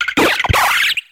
Catégorie:Cri Pokémon (Soleil et Lune) Catégorie:Cri de Piclairon